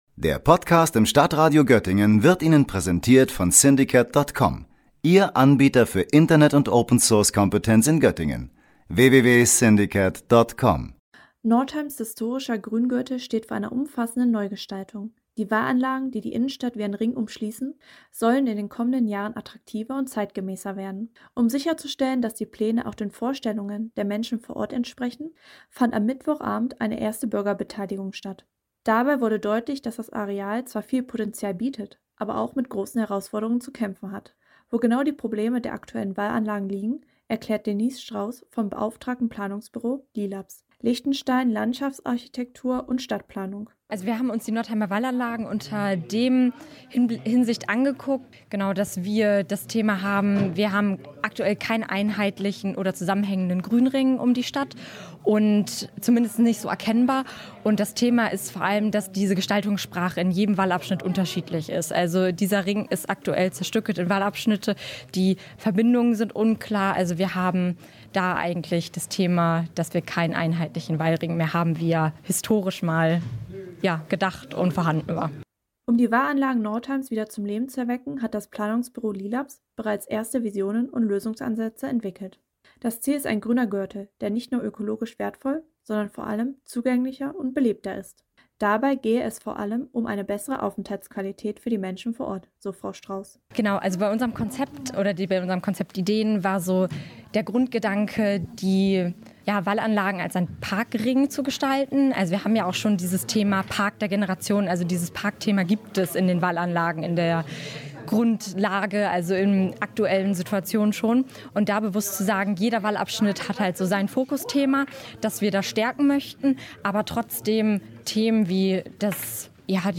Am Mittwoch, dem 4. Februar, fand dazu in der Stadthalle eine große Bürgerbeteiligung statt. Das Thema: Wie können die historischen Wallanlagen modernisiert und für die Zukunft fit gemacht werden?